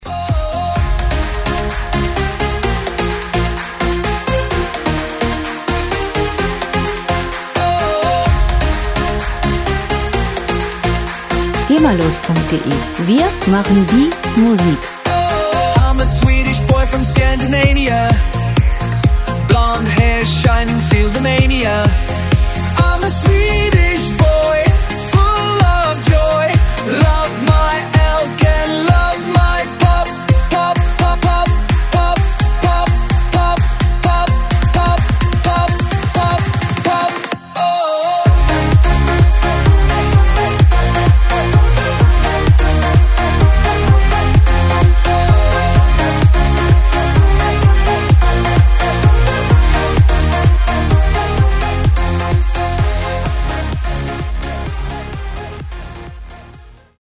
Musikstil: EDM
Tempo: 128 bpm
Tonart: Cis-Moll
Charakter: einprägsam, positiv
Instrumentierung: Synthesizer, schwedischer Popsänger